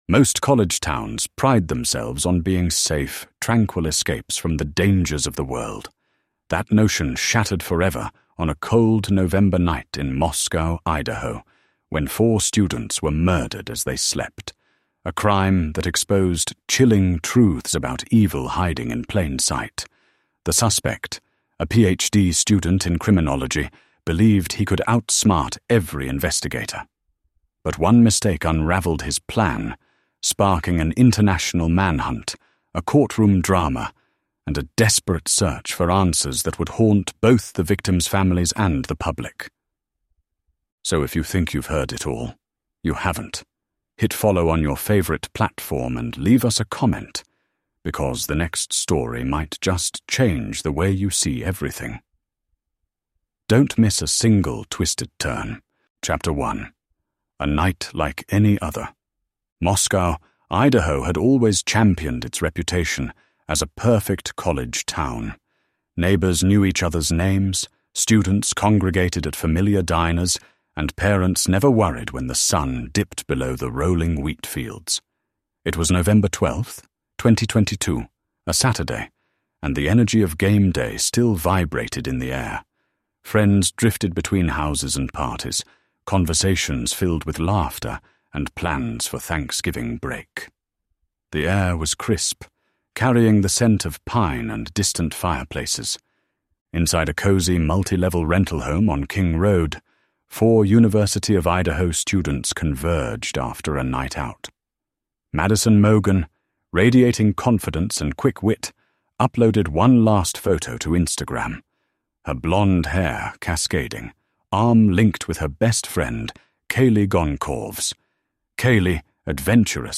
The Idaho student murders demolished the myth of the “safe college town,” revealing how disturbing evil can hide in plain sight. This true crime documentary uncovers the full story—from the shocking crime scene and tense investigation to the emotional courtroom and final sentencing.
Featuring exclusive courtroom drama and raw family testimony, this is true crime at its most real.